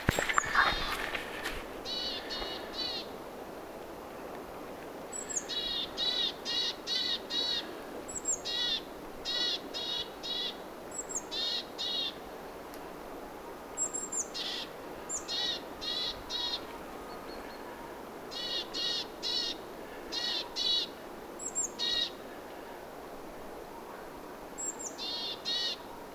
hömötiaislinnun huomioääntelyä
homotiaisen_huomioaantelya.mp3